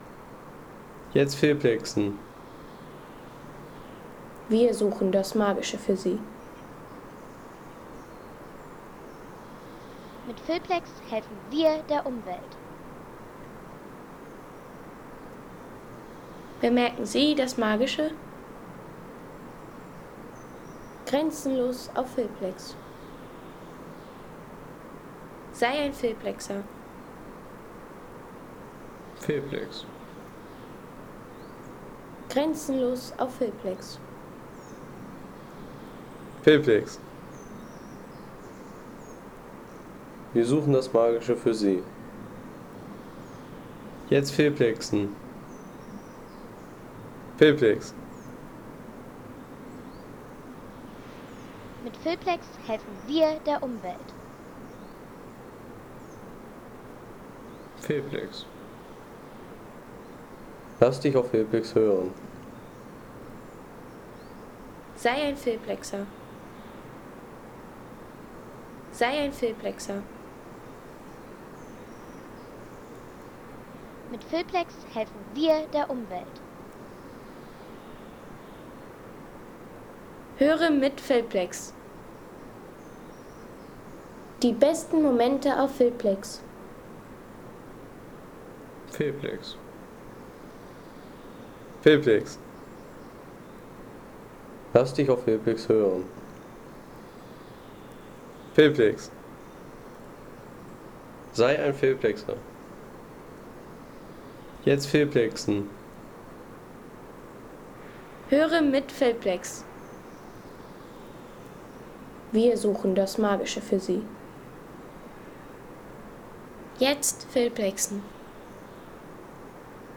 Ein Waldsound, der ganz sicher ein Geheimnis kennt
Geheimnisvolle Waldatmosphäre aus der Hexenhöhle im Märchenwald des Ötztals.
Geheimnisvolle Waldkulisse aus der begehbaren Hexenhöhle im Ötztaler Bergsturzwald mit magischer Ruhe und Märchenatmosphäre.